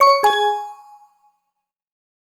distress.wav